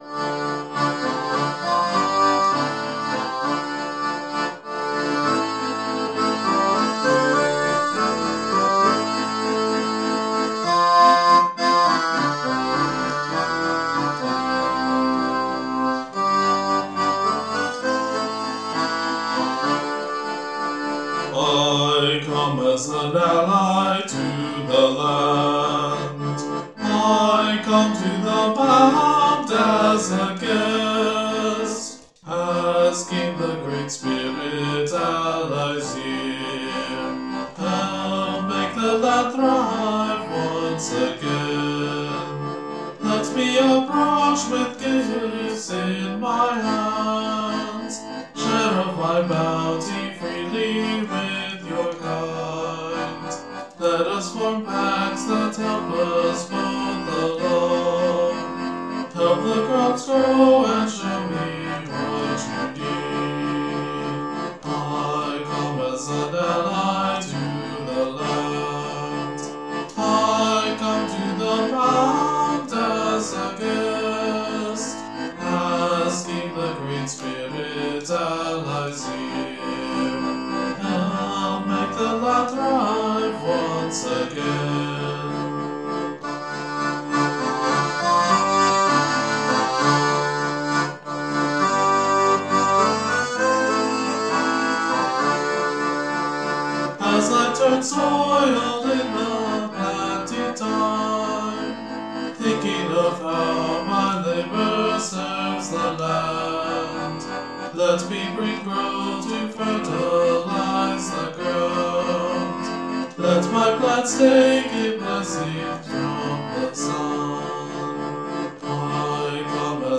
Musically, this all started from the first vocal line, and expanded out from there. The 6/4 rhythm feels a bit different from either 6/8 (a faster grouping of 3's commonly heard in jigs) but also different from the 3/4 because the first group of 3 is always incomplete. In addition, by having groups of 3 measures rather than the usual 4, it gets a slight surprise at the ends of phrases, at least at first. The scale here is D Dorian, which differs from the standard minor by having the B natural rather than B flat, which brings along with it the G major and E minor chords that normally aren't present in a D minor-ish key.